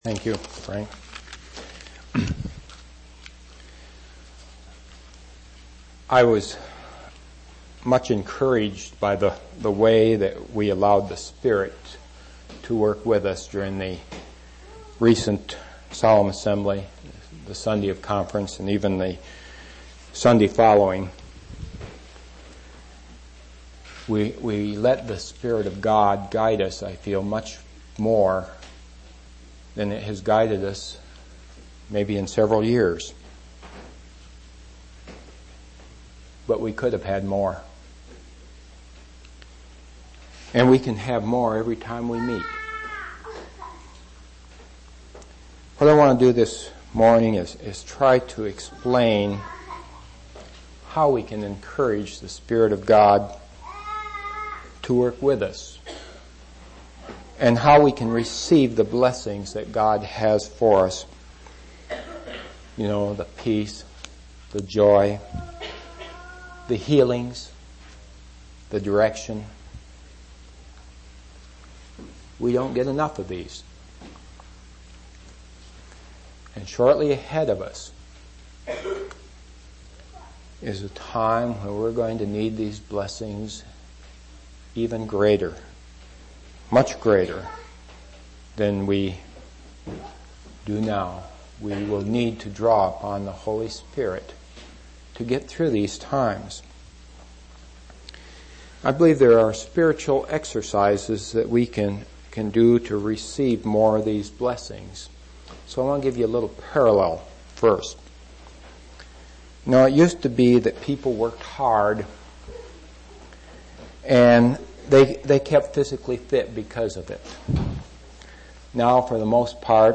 4/21/1991 Location: East Independence Local Event